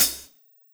Cymbol Shard 11.wav